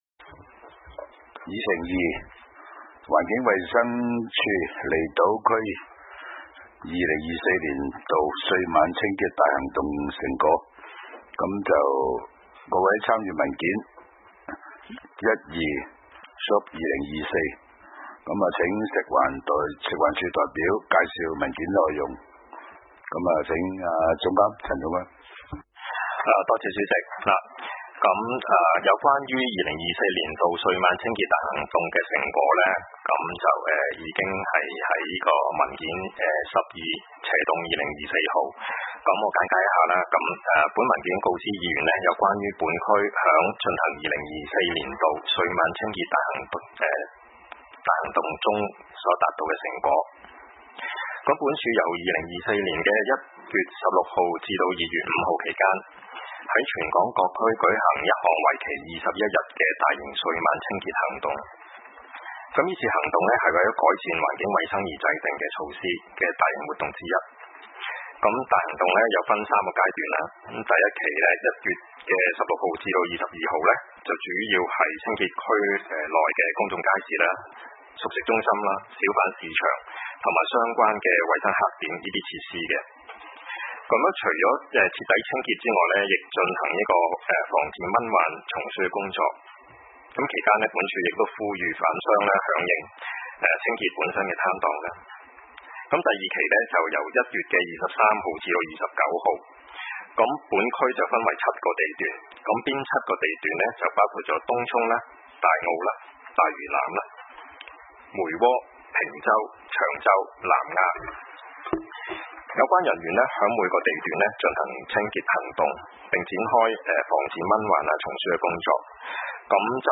離島區議會 - 委員會會議的錄音記錄